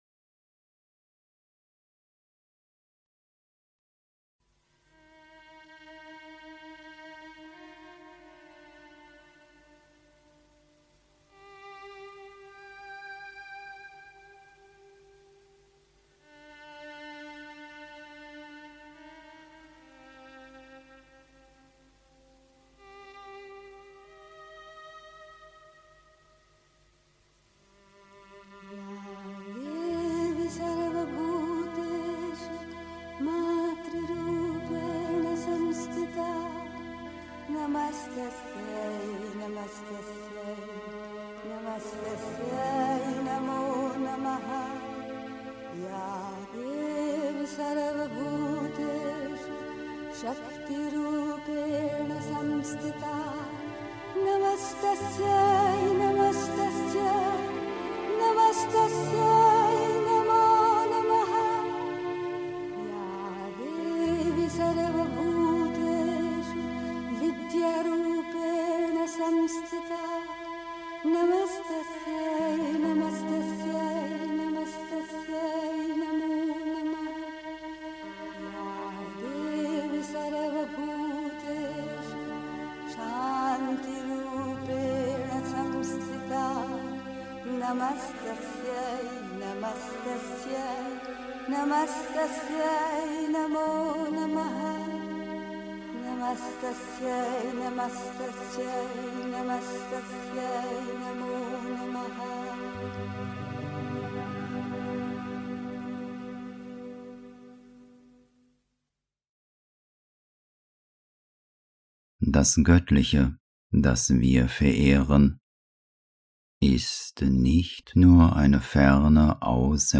Pondicherry. 2. Das Göttliche, das wir verehren (Sri Aurobindo, CWSA, Vol. 23, S. 74) 3. Zwölf Minuten Stille.